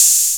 808-OpenHiHats12.wav